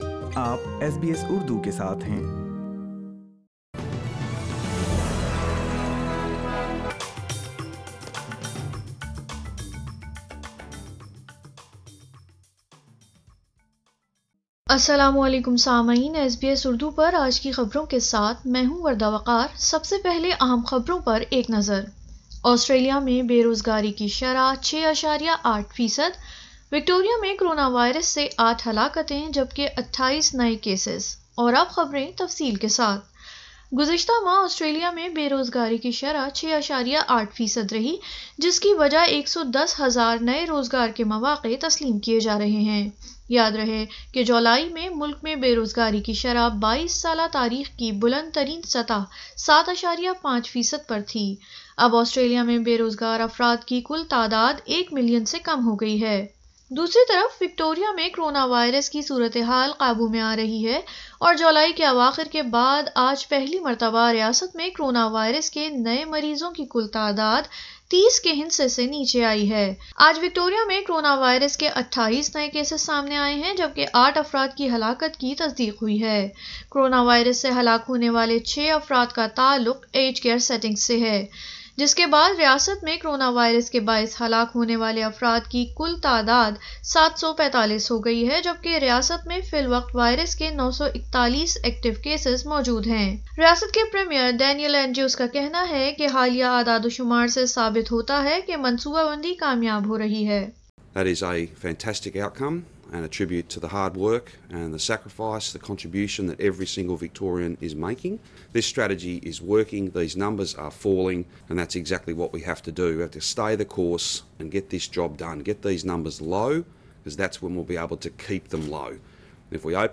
ملک میں بے روزگاری کی شرح میں کمی جبکہ سرحدی پابندیوں میں بہتری کے لئے وزیر اعطم اسکاٹ موریسن پر امید ۔سنئے اردو خبریں